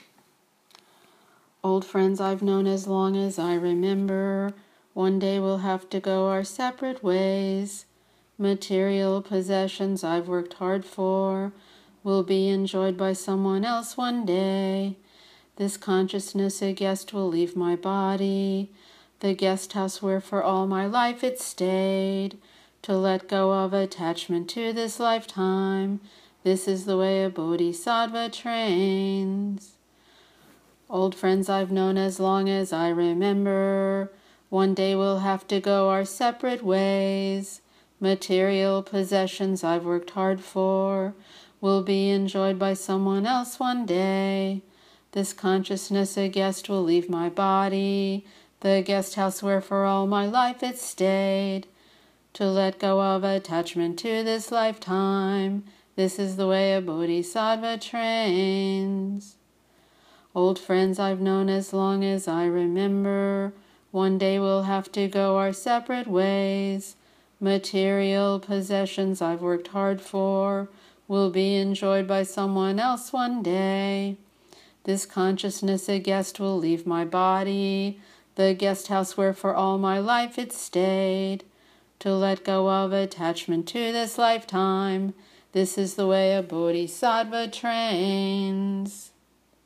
verse 4 chanted 3x